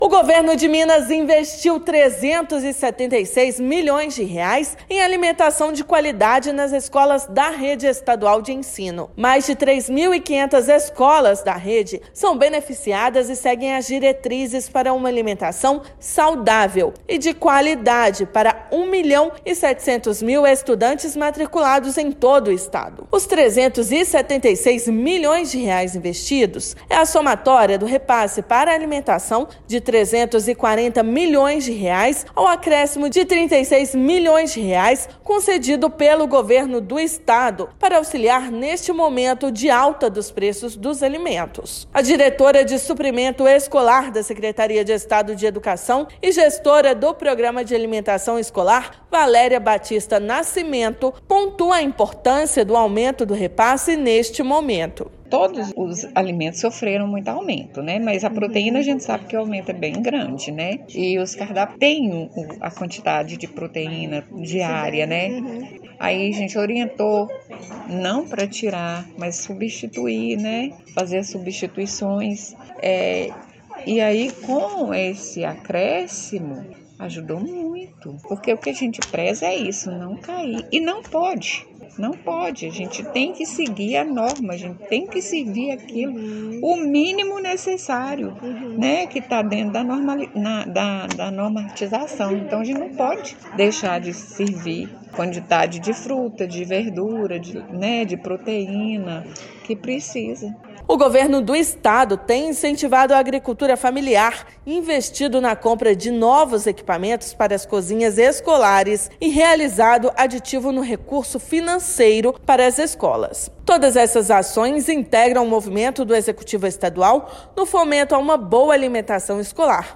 [RÁDIO] Governo de Minas investe R$ 376 milhões em alimentação de qualidade nas escolas da rede estadual de ensino
Incentivo à agricultura familiar, investimentos para compra de novos equipamentos para as cozinhas e aditivo no recurso financeiro integram ações de fomento à uma boa alimentação escolar. Ouça matéria de rádio.